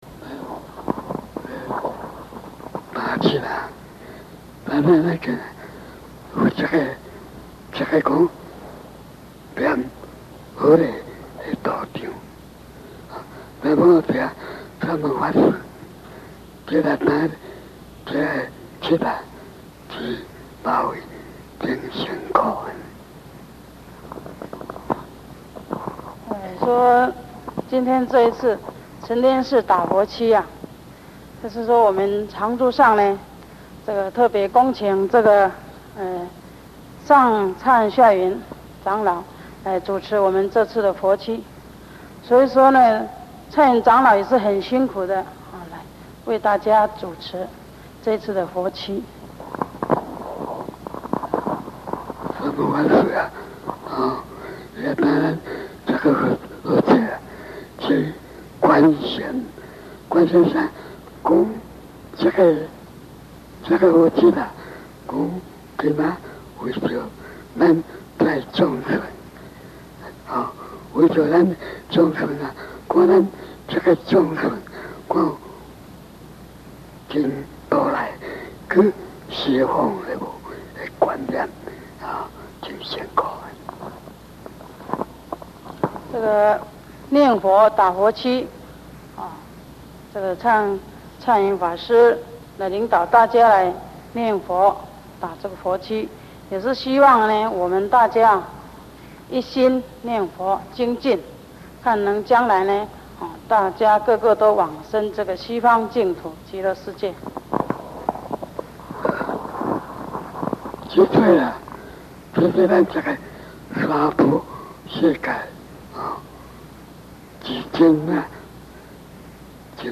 GQKS71-9.mp3 檔案下載 - 佛學多媒體資料庫 佛學多媒體資料庫 > 佛學講座 > 佛學講座-聲音檔 > 廣欽老和尚 > 勸修念佛法門-現場錄音 > GQKS71-9.mp3 > 檔案下載 Download 下載: GQKS71-9.mp3 ※MD5 檢查碼: 6165E25EDAF0F0007EED2F62D2A6A827 (可用 WinMD5_v2.exe 檢查下載後檔案是否與原檔案相同)